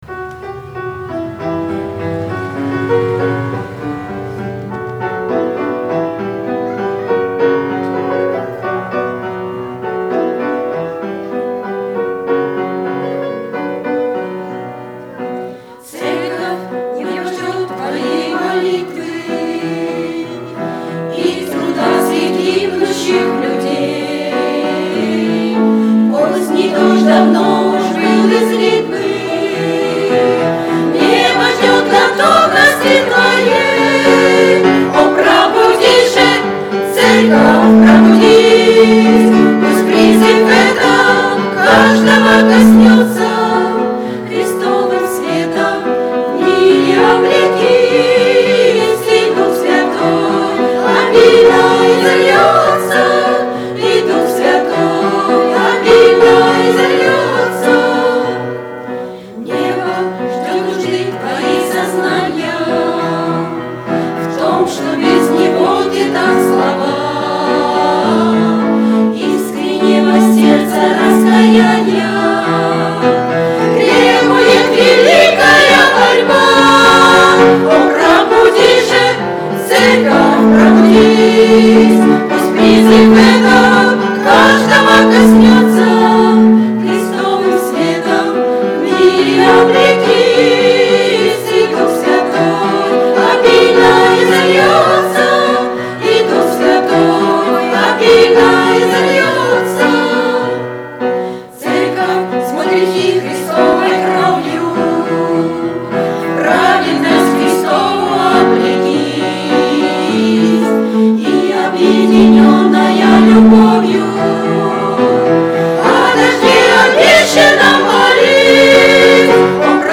on 2014-07-08 - Фестиваль христианской музыки и песни